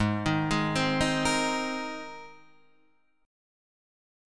Listen to G#add9 strummed